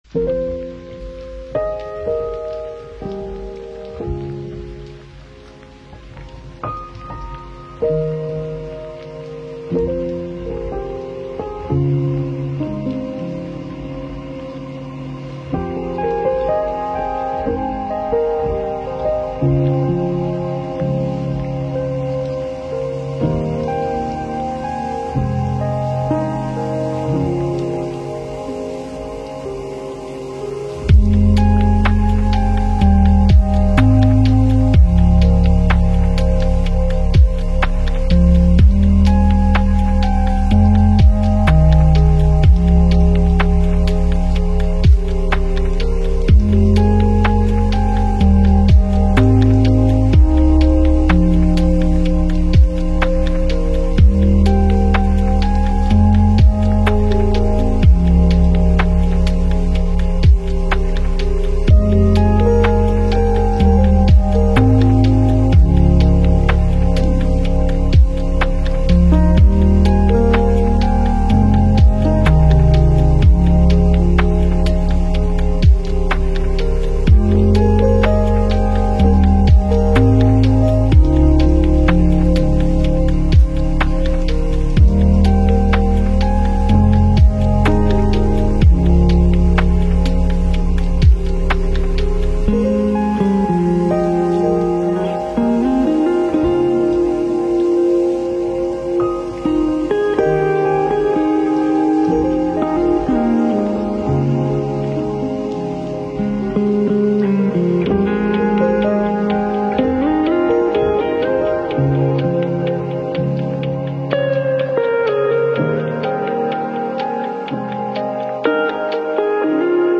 Мы подобрали для вас лучшие lo-fi песни без слов.
Осенняя мелодия 🍂